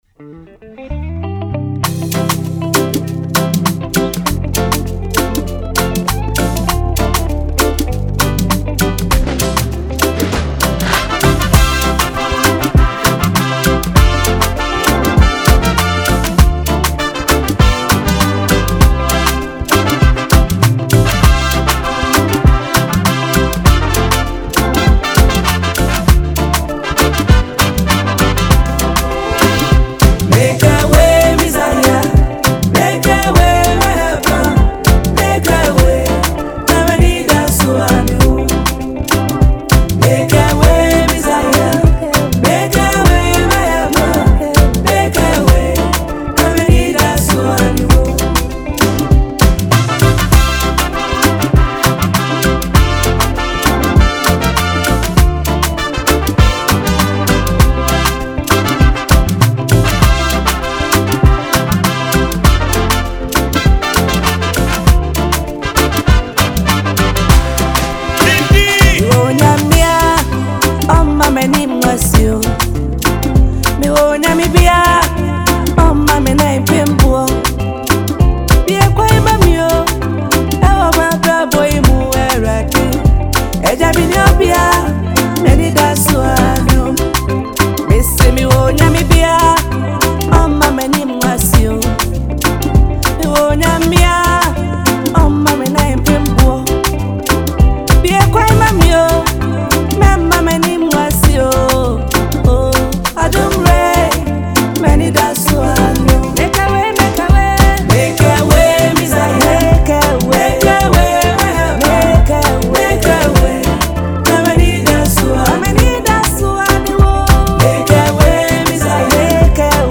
Gospel singer
With its moving lyrics and soul-stirring melody
By blending powerful lyrics with an emotive delivery